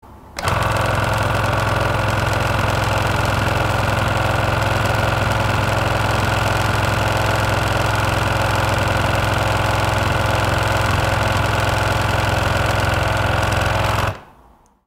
Звук работы ирригатора Waterpik WP 260